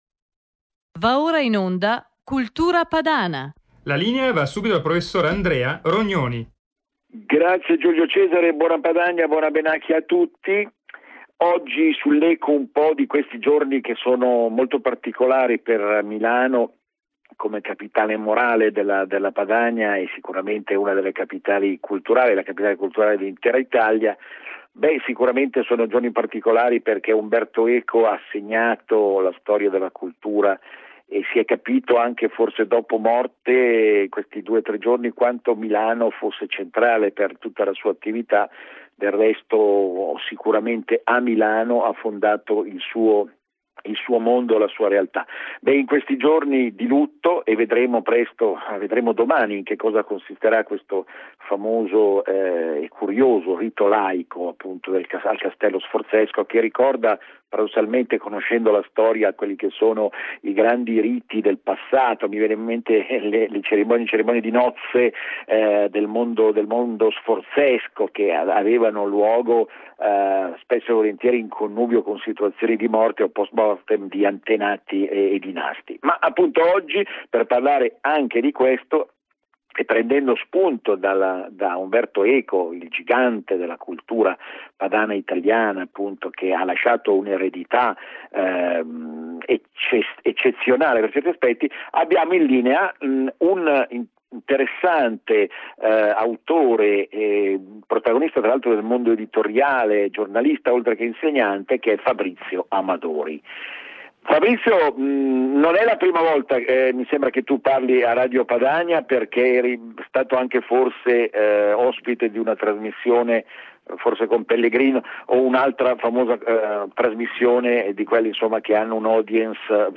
INTERVISTA A RADIOPADANIA del 22/2/2016 IN OCCASIONE DELLA MORTE DI UMBERTO ECO